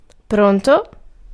Ääntäminen
Ääntäminen US : IPA : [ˈwɪl.ɪŋ]